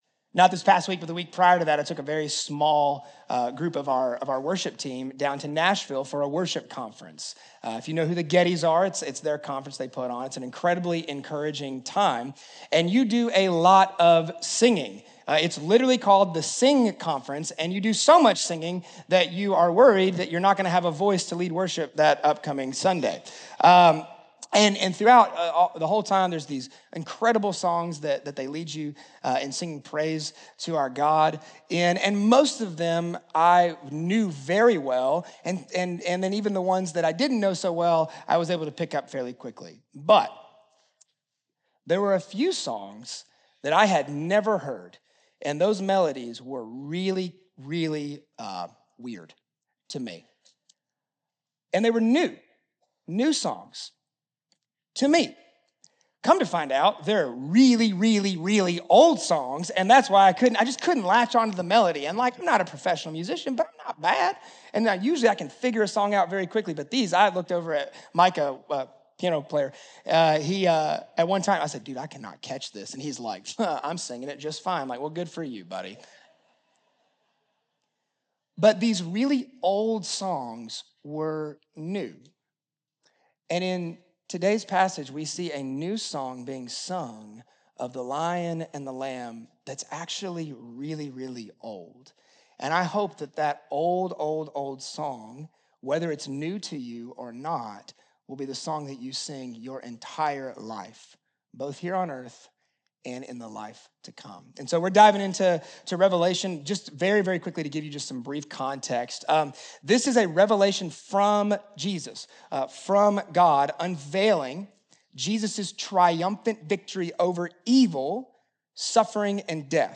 Ashland Sermon (Revelation 5: 1-14)